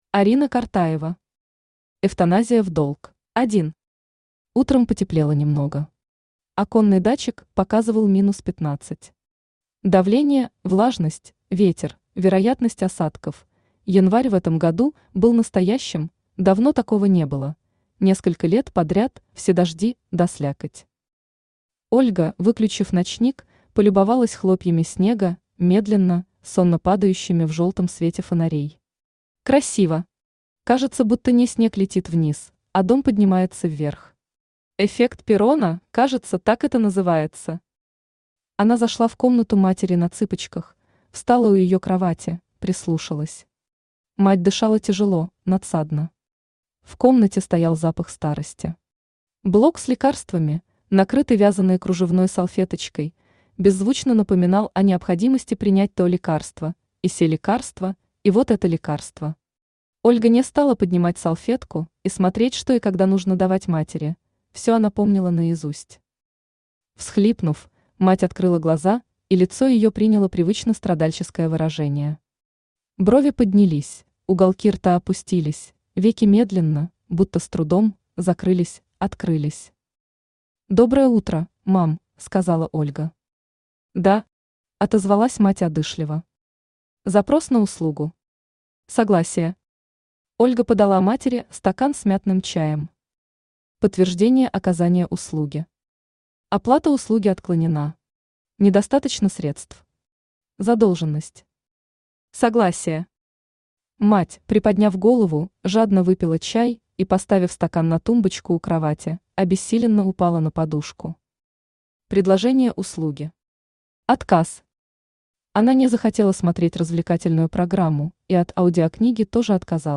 Аудиокнига Эвтаназия в долг | Библиотека аудиокниг
Aудиокнига Эвтаназия в долг Автор Орина Ивановна Картаева Читает аудиокнигу Авточтец ЛитРес.